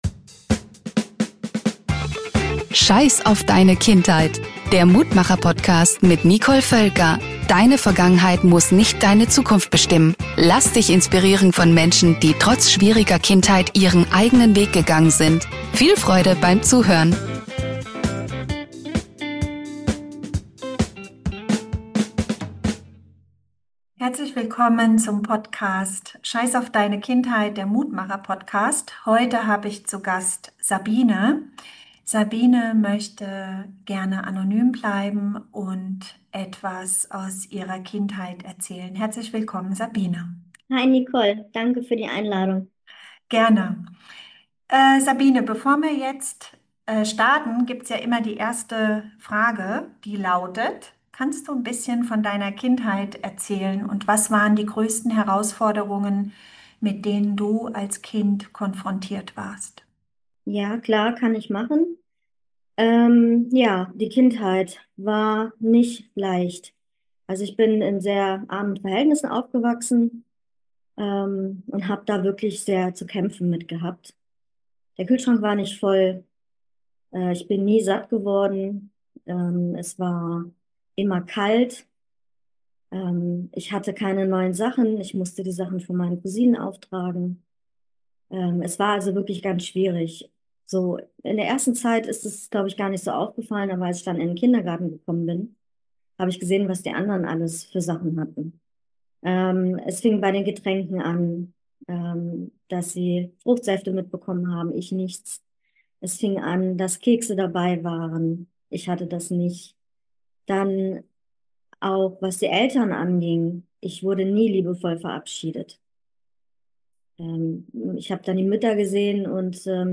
unter verstellter Stimme